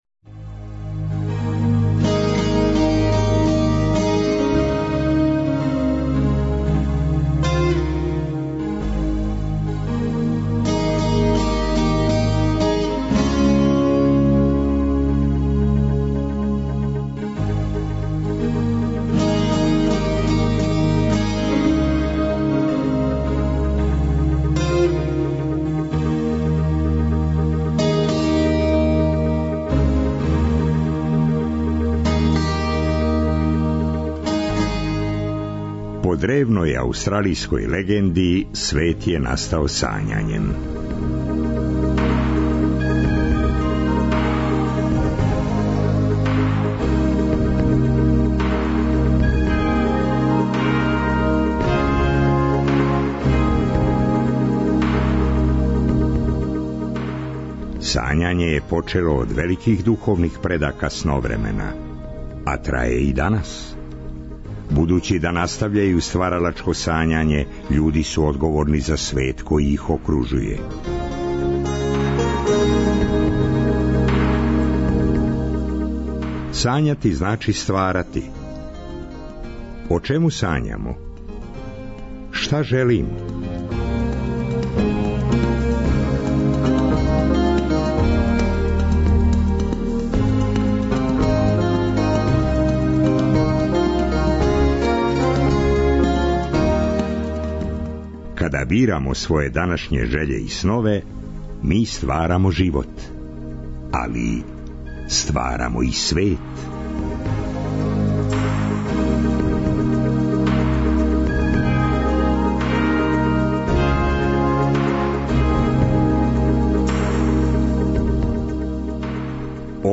Кућа у Крагујевцу, пројекат 'PolyArt Factory'.
У трећем сату Сновремена чућемо снимак са сајамске промоције новог интернет часописа "No Rules".